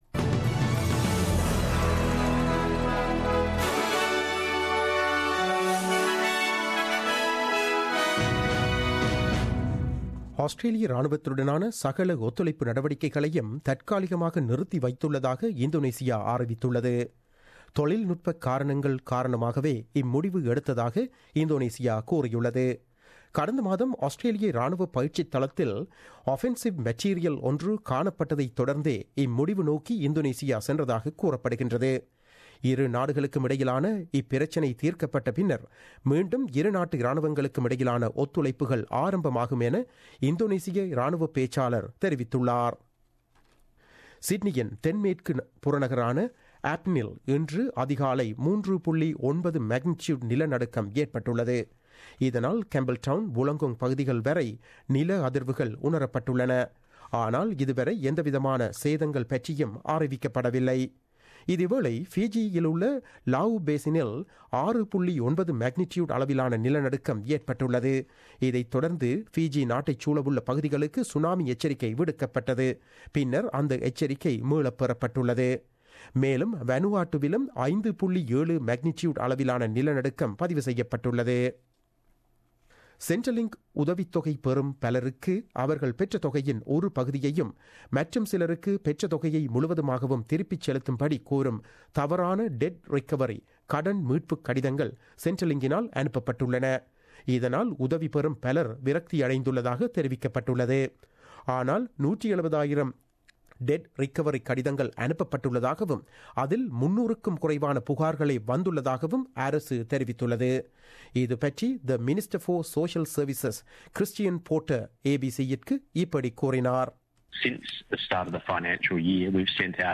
The news bulletin aired on 04 January 2017 at 8pm.